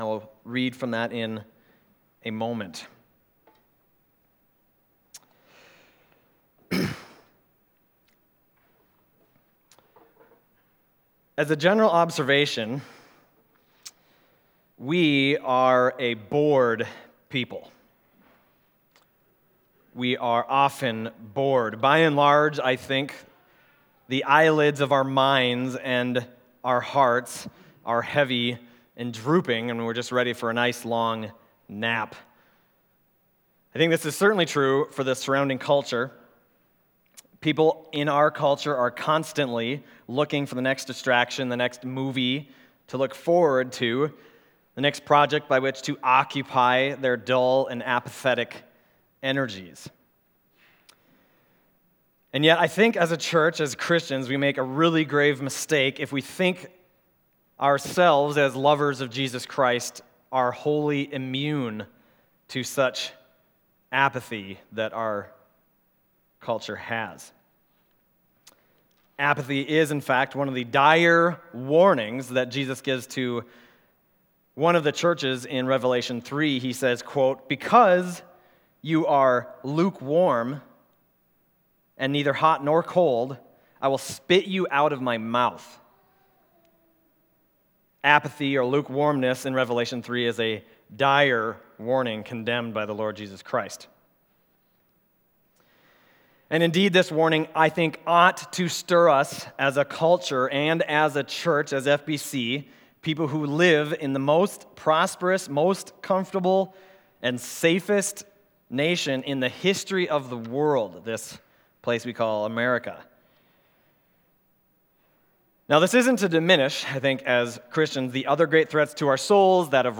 Other Passage: Romans 11:33-36 Service Type: Sunday Morning Romans 11:33-36 « Your Kingdom Come